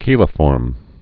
(kēlə-fôrm)